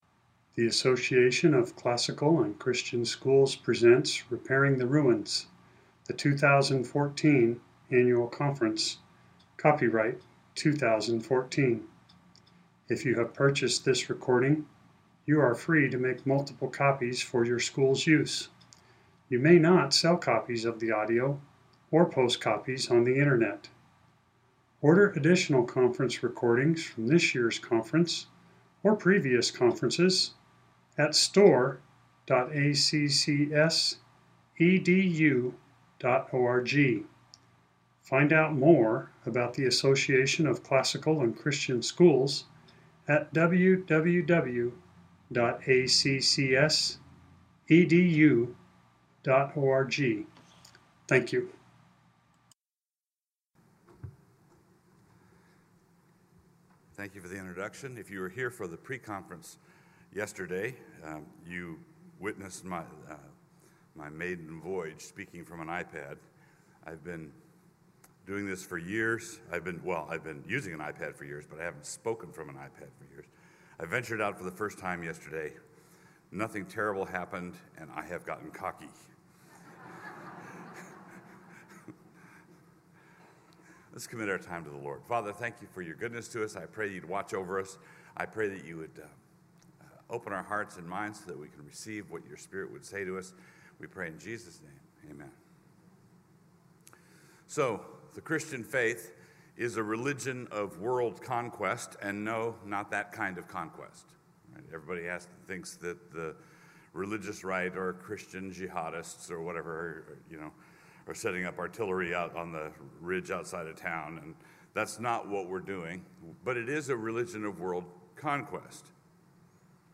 2014 Workshop Talk | 0:46:28 | Culture & Faith
Jan 18, 2019 | Conference Talks, Culture & Faith, Library, Media_Audio, Plenary Talk | 0 comments